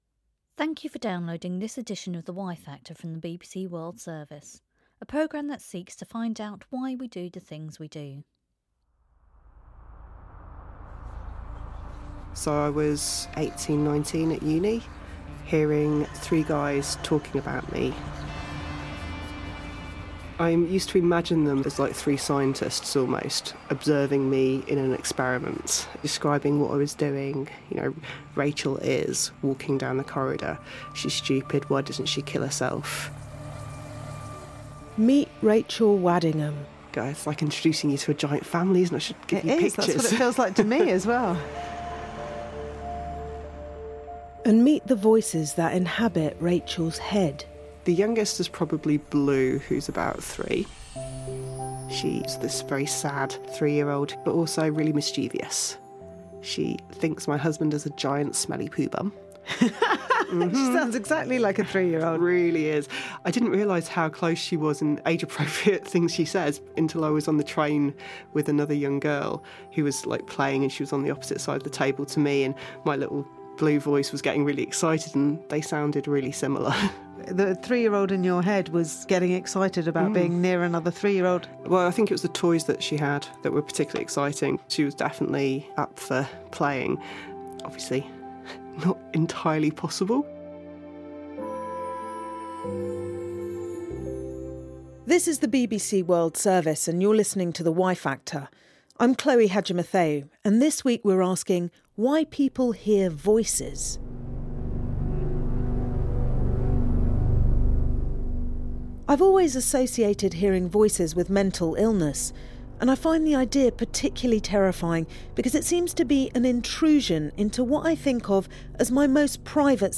by Hearing the Voice | Jan 10, 2018 | Announcements, Cultural & Media Events, HtV team members, Interviews & Talks